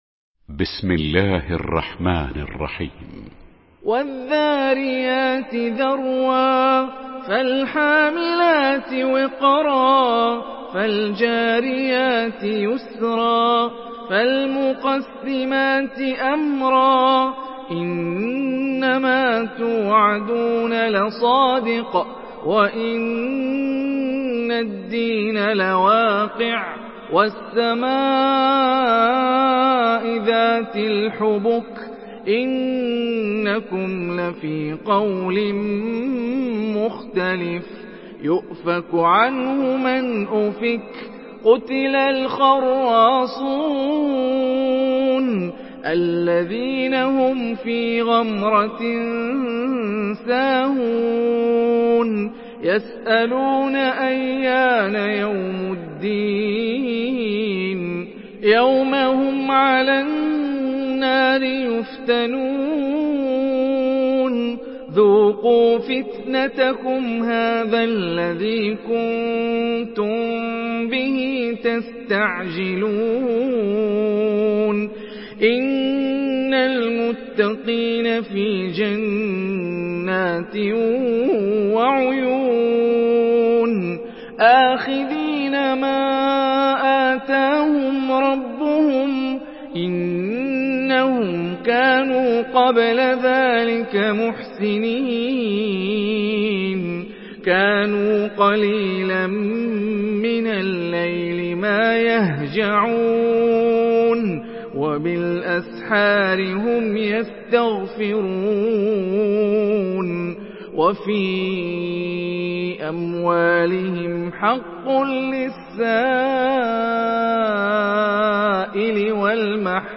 سورة الذاريات MP3 بصوت هاني الرفاعي برواية حفص عن عاصم، استمع وحمّل التلاوة كاملة بصيغة MP3 عبر روابط مباشرة وسريعة على الجوال، مع إمكانية التحميل بجودات متعددة.
مرتل